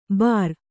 ♪ bār